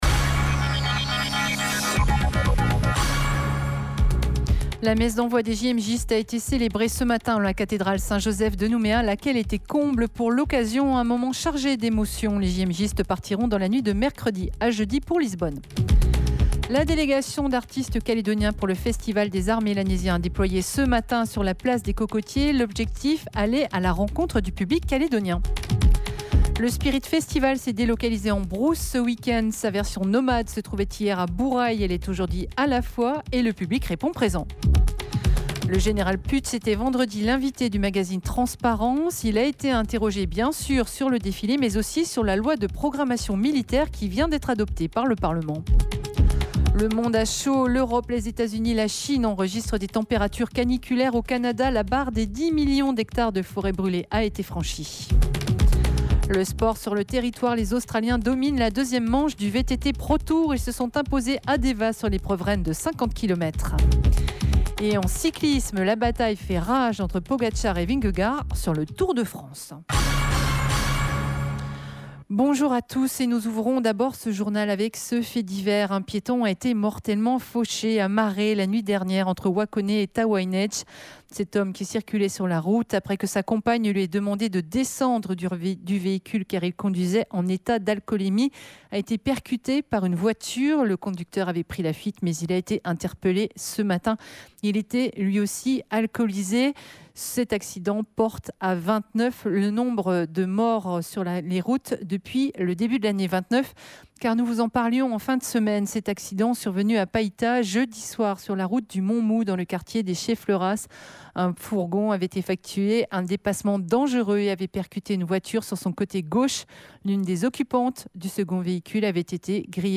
JOURNAL : INFO WEEK END DIMANCHE MIDI